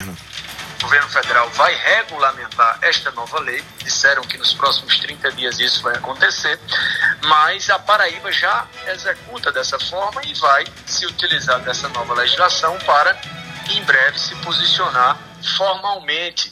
Em entrevista ao programa Arapuan Verdade, da Rádio Arapuan FM desta terça-feira (14/01), o secretário de Educação da Paraíba, Wilson Filho, afirmou que a metodologia de ensino aplicada no Estado já adere essa medida, e que após diretrizes apresentadas pelo Governo Federal irá se posicionar detalhadamente acerca do tema.